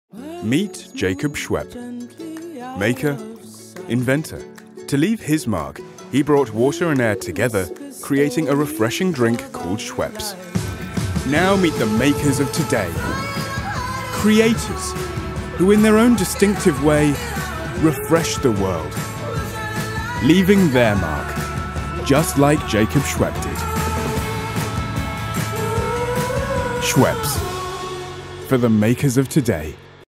Hugely flexible, his voice can move from gritty urban to assured corporate to inviting and warm and anywhere in between.
britisch
Sprechprobe: Werbung (Muttersprache):